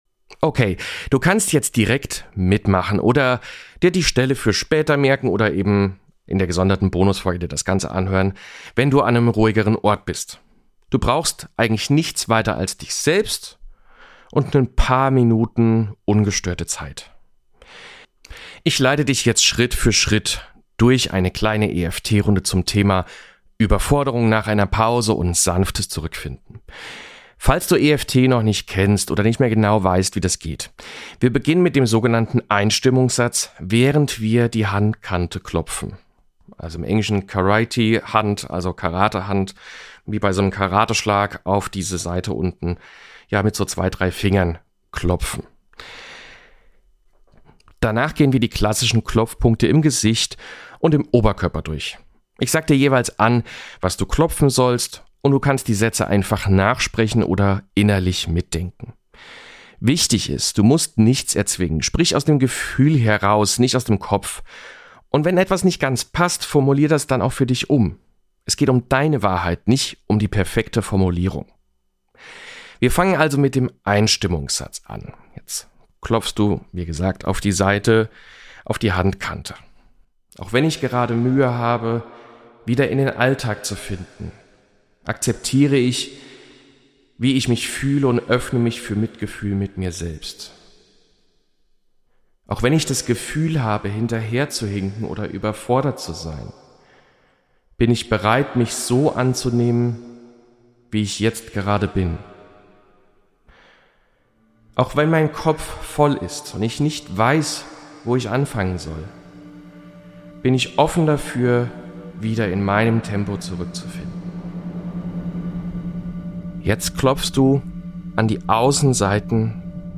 Geführte EFT-Klopfübung für emotionale Klarheit und innere Ruhe nach Pausen oder Feiertagen.